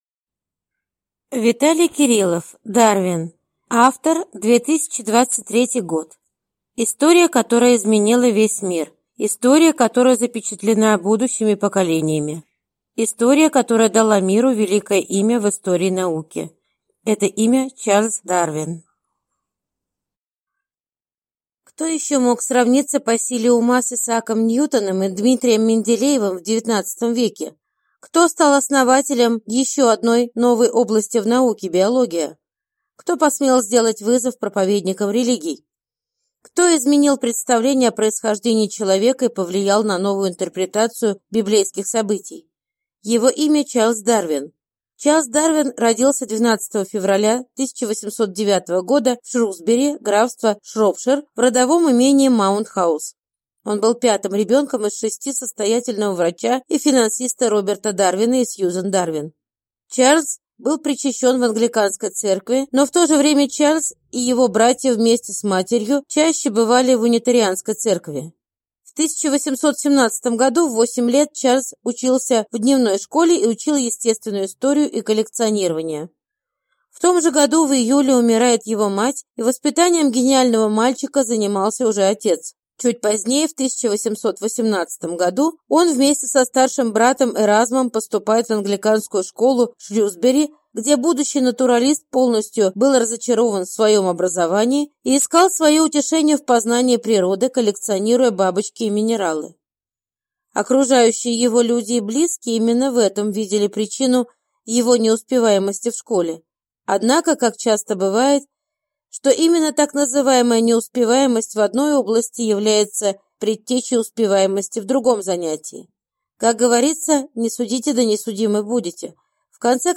Аудиокнига Дарвин | Библиотека аудиокниг